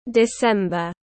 Tháng 12 tiếng anh gọi là december, phiên âm tiếng anh đọc là /dɪˈsem.bər/
December /dɪˈsem.bər/
December.mp3